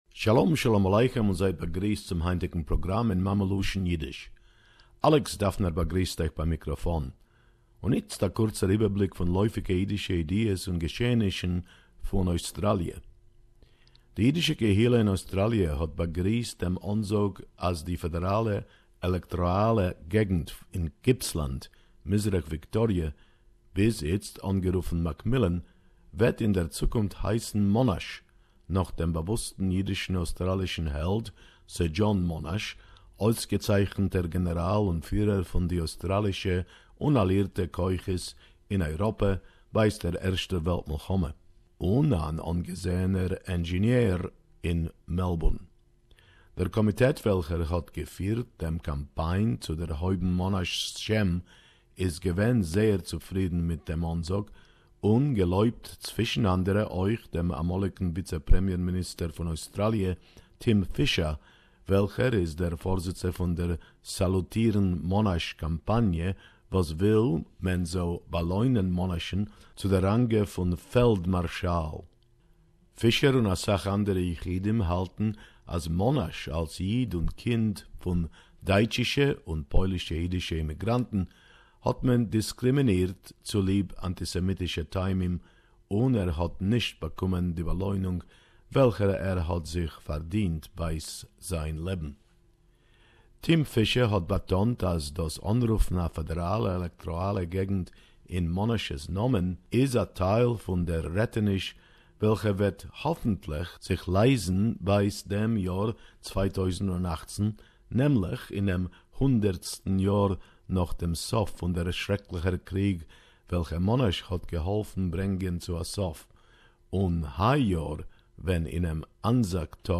The Seat of Mc Millan will be renamed Monash...Yiddish report 15.4.2018